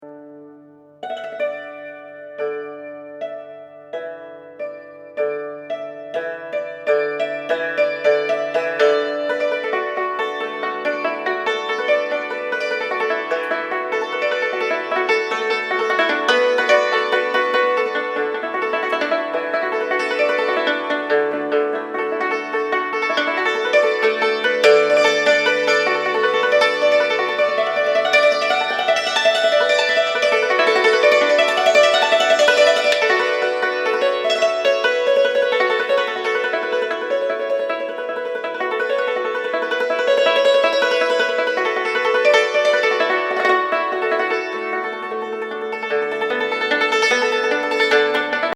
ツィター属打弦楽器ダルシマーで、中国古典音楽を演奏した一枚!幽玄
な中華ムードと、クセになるダルシマーの音色が相まって◎ダルシマー・